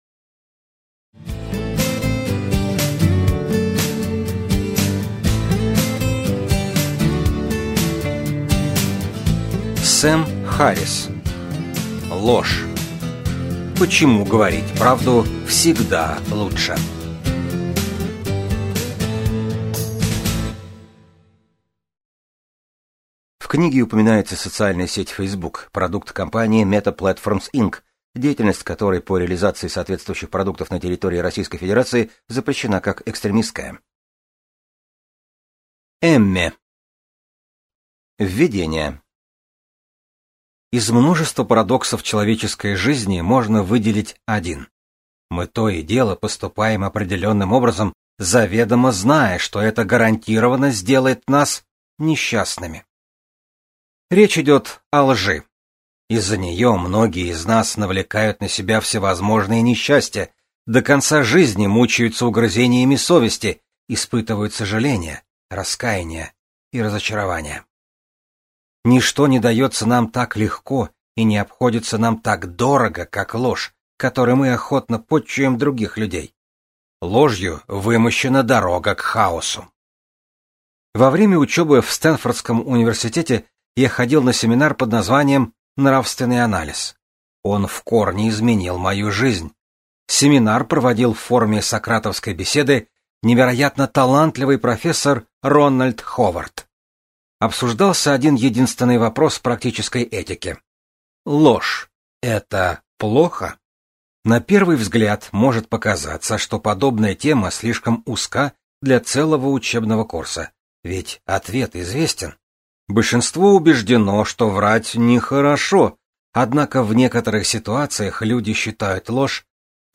Аудиокнига Ложь: Почему говорить правду всегда лучше | Библиотека аудиокниг